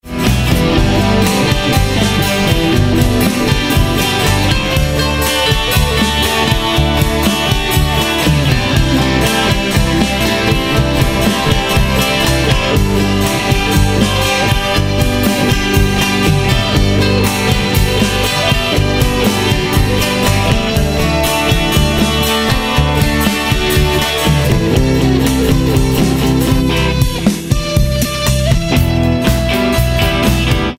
Kurzer Ausschnitt aus einem Song, den ich gerade mache, gespielt mit Akustic, Squier (// Edith Bulette :mampf:) ;) Bullet Strat und meine ML-Tele.:cool:
also mal im ernst, ich höre da nur wildes durcheinander!!:confused::eek:
ob das wohl am mixen lag?:gruebel: finde einiges zu laut geraten....
Stimmt schon, fertig abgemischt u.gemastert ist das Ding auch noch nicht.